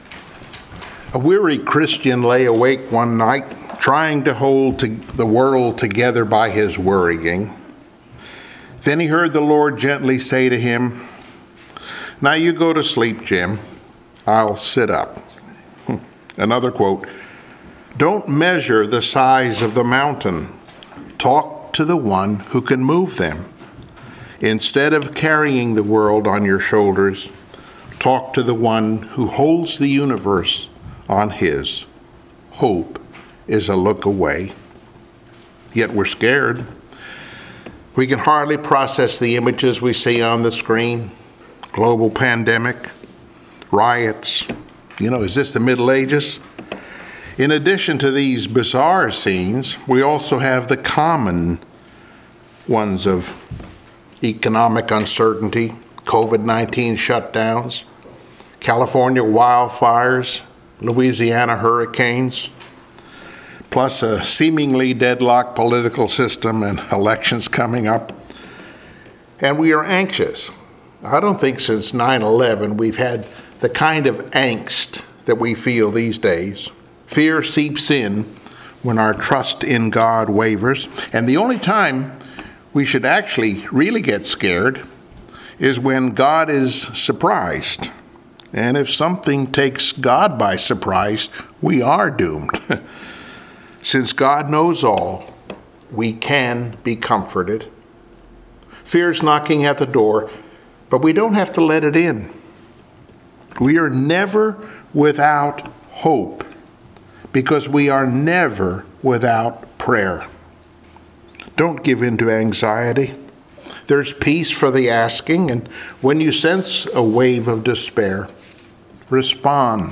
Philippians 4:4-6 Service Type: Sunday Morning Worship Topics